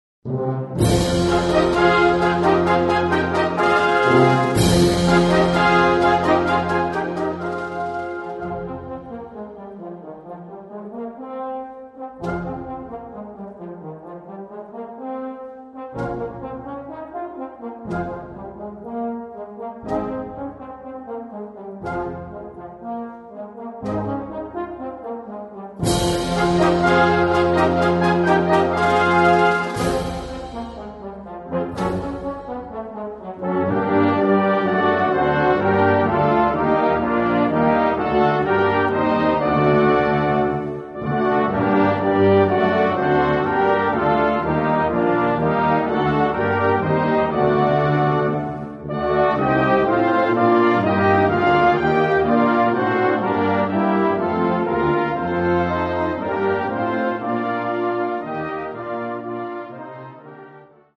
Genre: Brass Ensemble